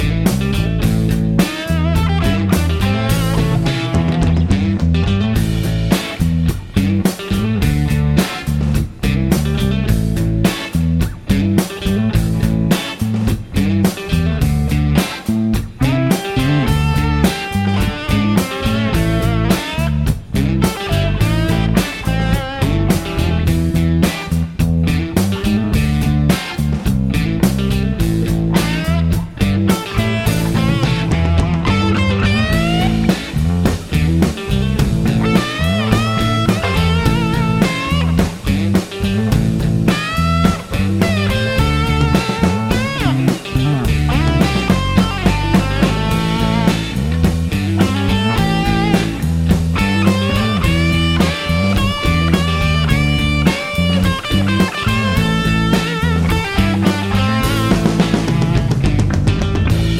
Shortened Version Blues 3:05 Buy £1.50